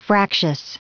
Prononciation du mot fractious en anglais (fichier audio)
Prononciation du mot : fractious